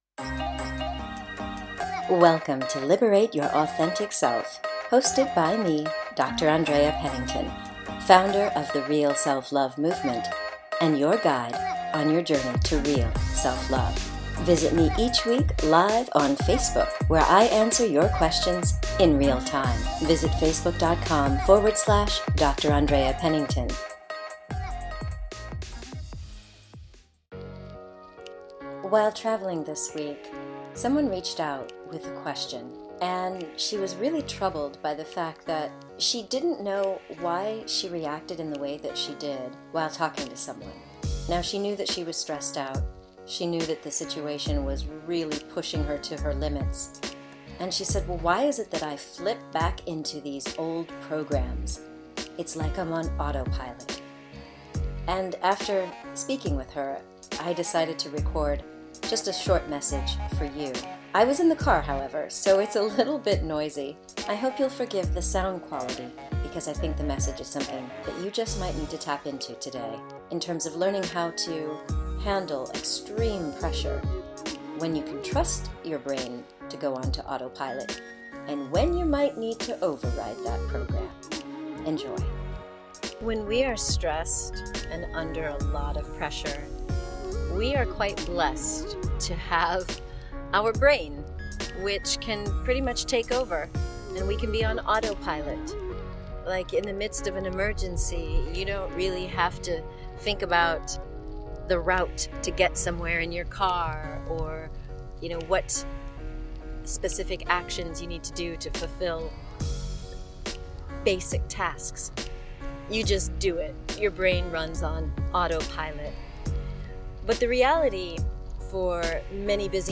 The conversation sparked a desire in me to share a message with you, so I recorded it while in the car. Sadly the audio quality is not my usual best, so I ask for your forgiveness.